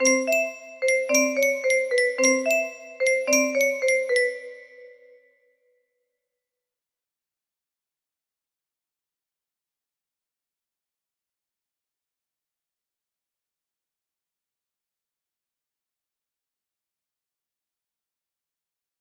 for new project music box melody